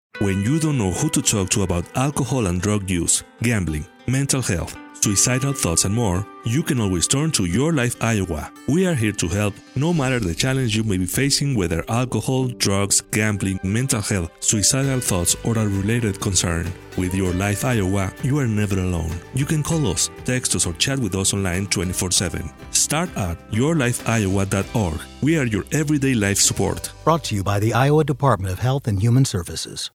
:30 Radio Spot | YLI Awareness (Male-3)